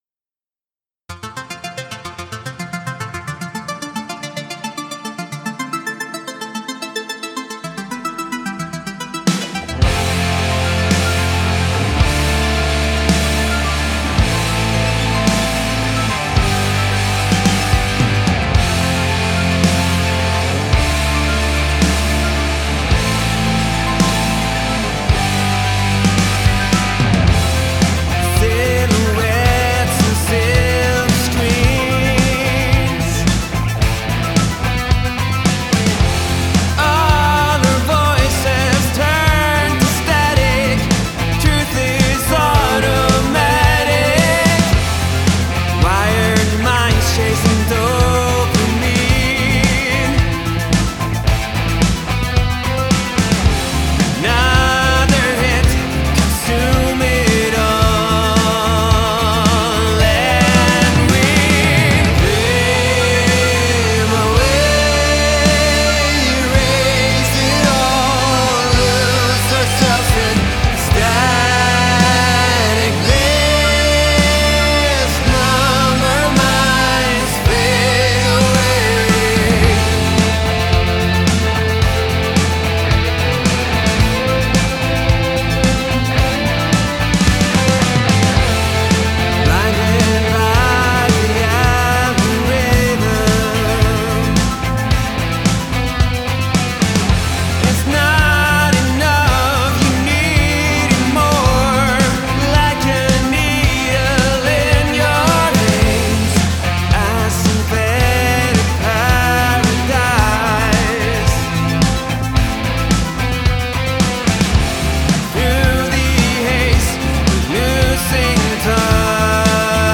On the first I made the attack more smooth on the synth and not as harsh. The second is without synth in the two first verses.